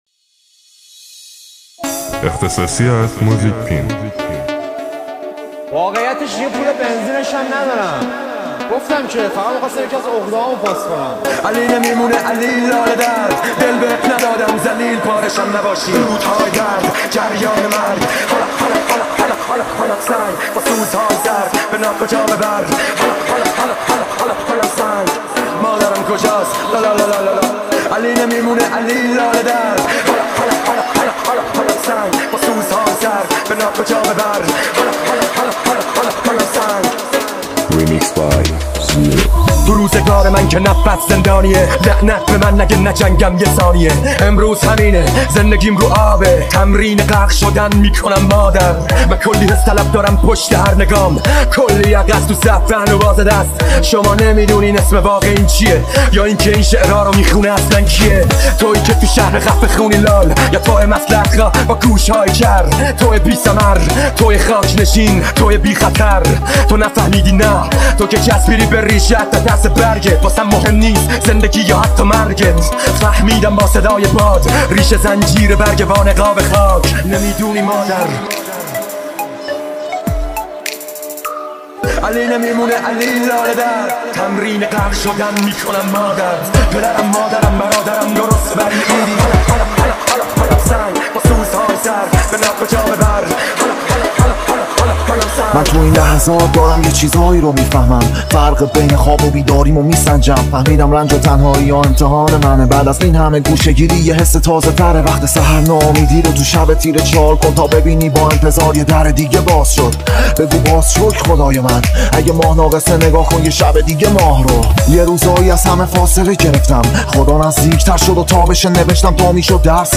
دانلود ریمیکس رپ فارسی